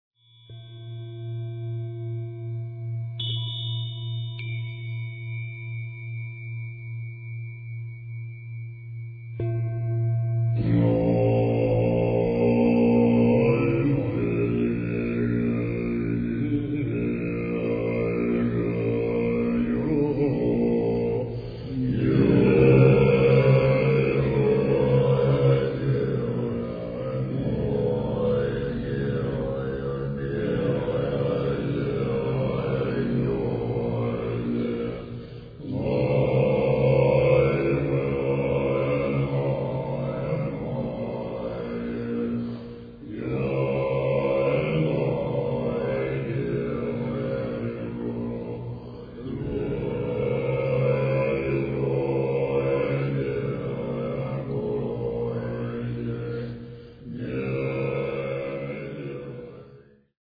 Tibetanische Mönche singen Om mani Padme Hum u.a.